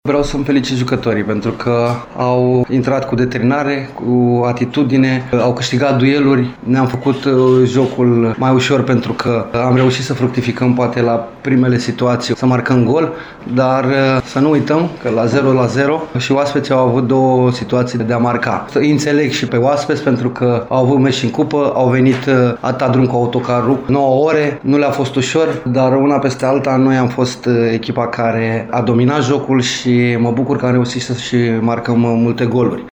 Antrenorul cărășenilor, Flavius Stoican, consideră important că ACSM a marcat de la primele ocazii: